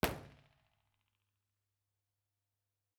IR_EigenmikePL001L2_processed.wav